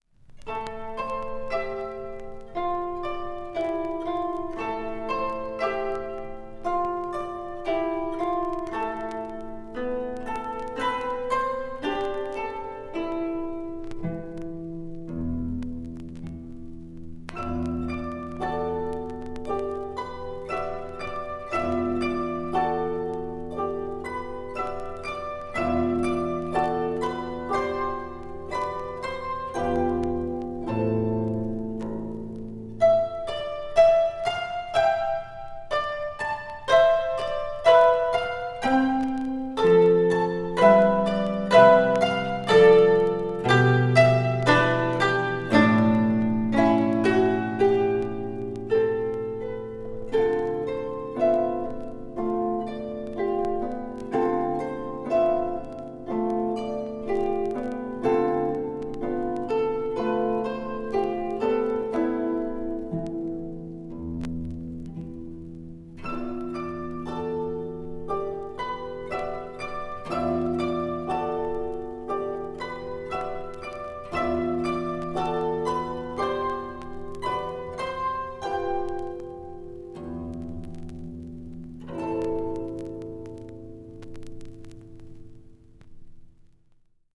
わずかにチリノイズが入る箇所あり